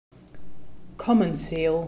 uttale); Harbor Seal